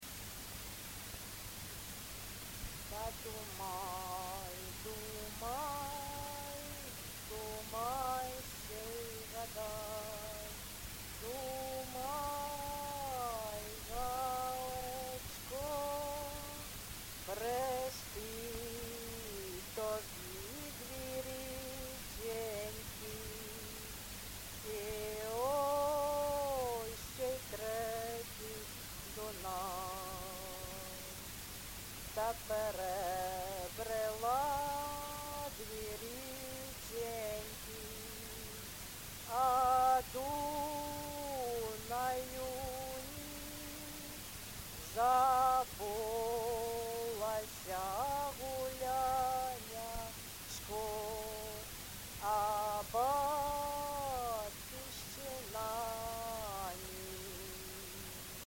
ЖанрВесільні
Місце записум. Ровеньки, Ровеньківський район, Луганська обл., Україна, Слобожанщина